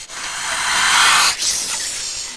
c_viper_no.wav